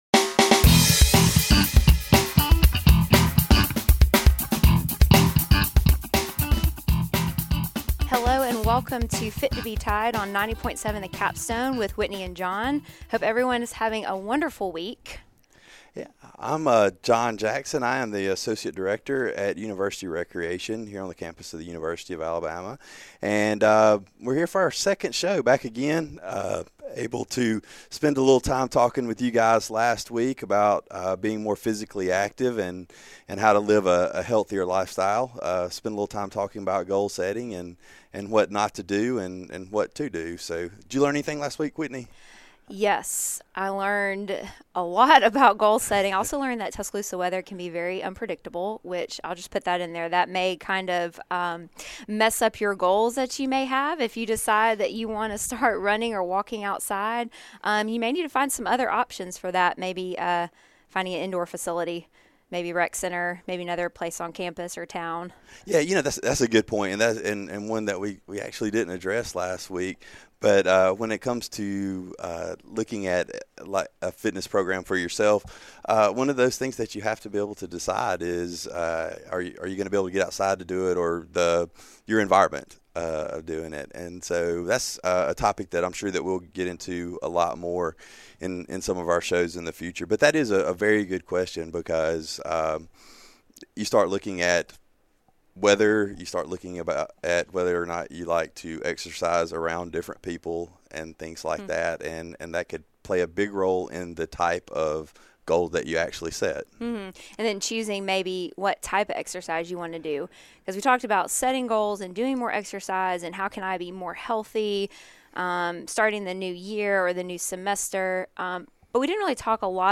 Originally aired 02/07/2016 on WVUA 90.7 FM, Tuscaloosa, AL.